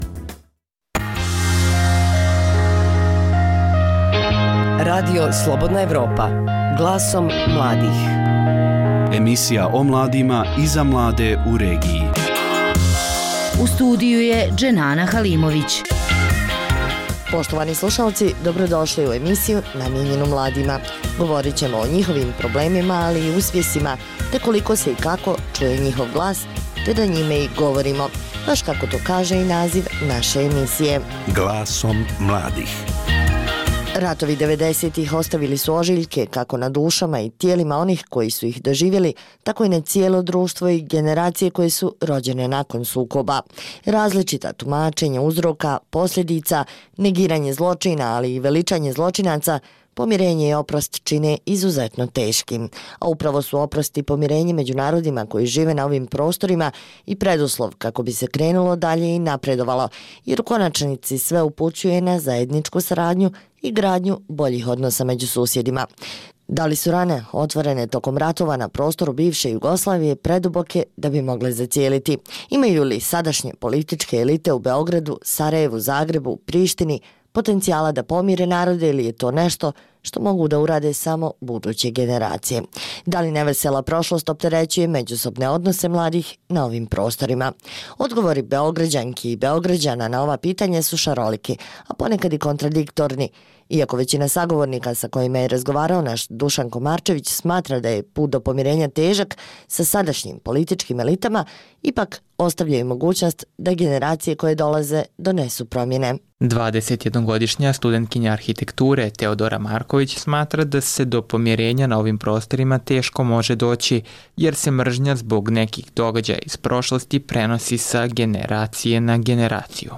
Da li nevesela prošlost opterećuje međusobne odnose mladih na ovim prostorima? Poslušajte šta mladi o tome kažu.